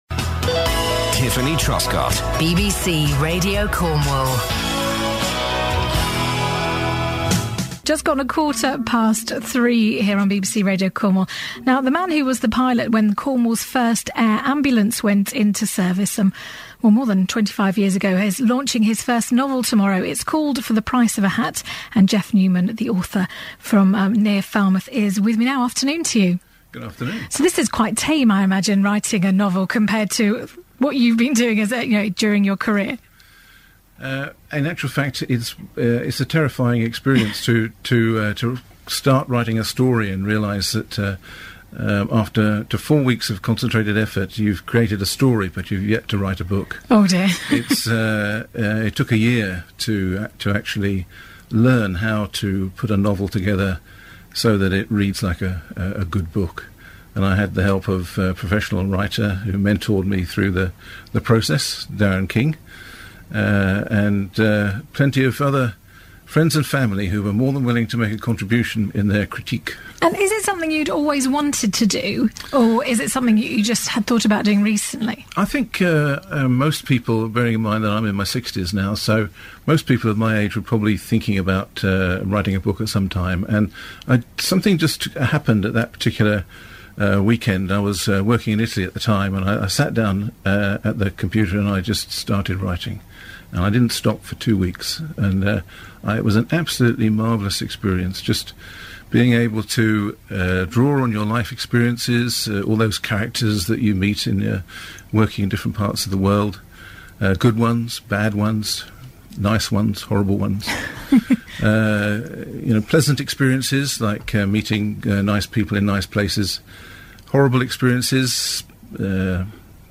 Radio Cornwall Interview